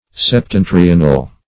Search Result for " septentrional" : The Collaborative International Dictionary of English v.0.48: Septentrion \Sep*ten"tri*on\, Septentrional \Sep*ten"tri*on*al\, a. [L. septentrionalis: cf. F. septentrional.] Of or pertaining to the north; northern.